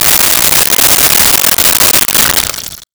Liquid Pour Into Cup 02
Liquid Pour into Cup 02.wav